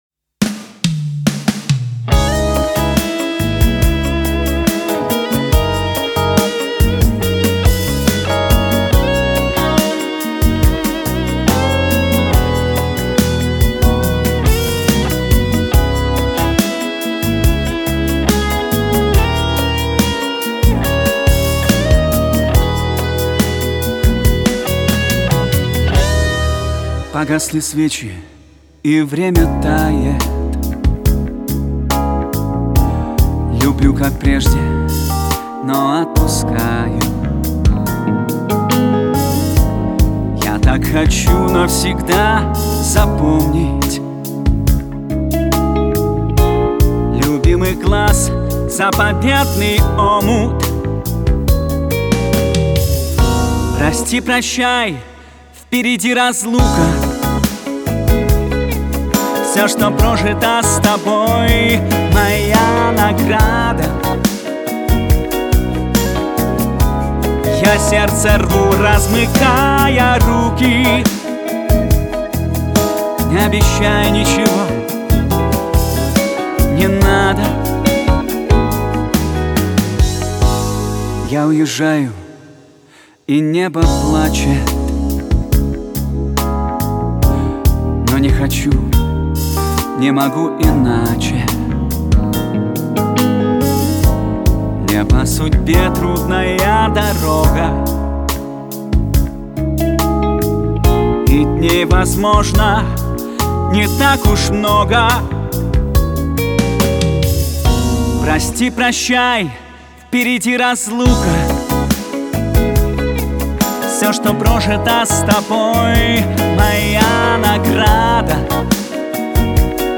новая лирика